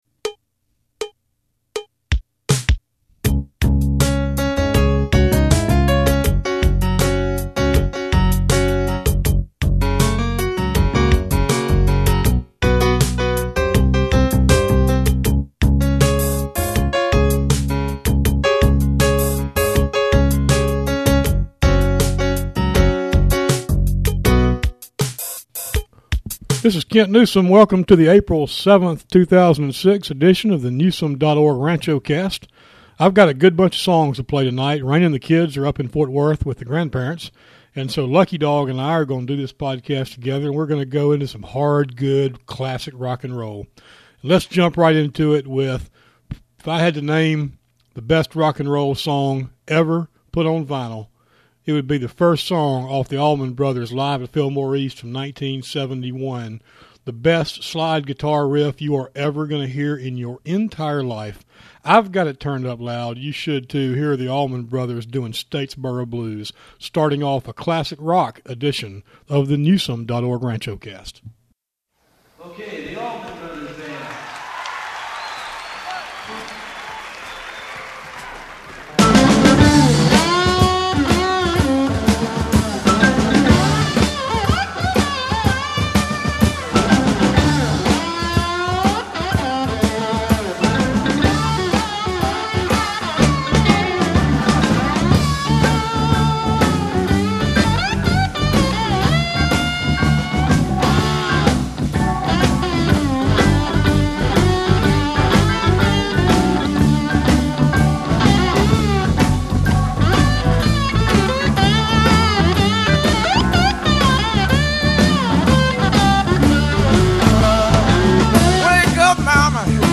We played some July 4th related songs
I ended the podcast by playing 6 great songs in a row